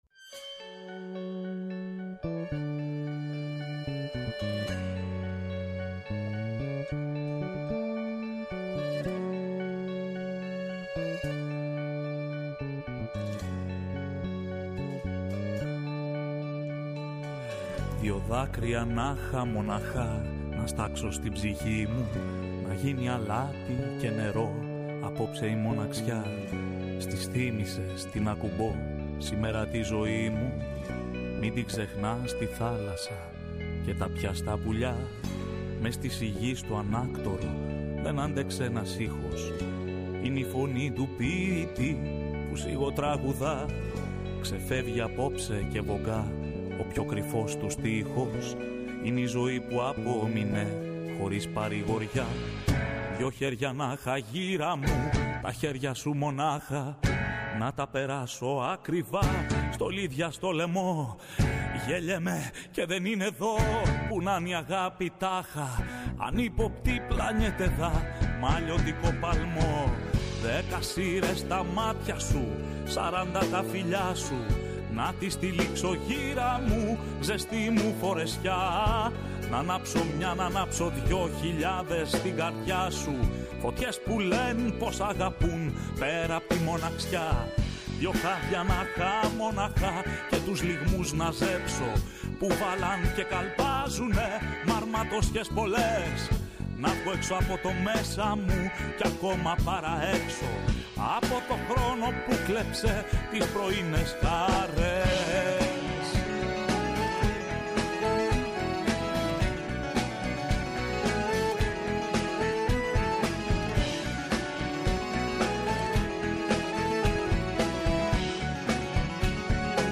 ΔΕΥΤΕΡΟ ΠΡΟΓΡΑΜΜΑ Παντος Καιρου Μουσική Συνεντεύξεις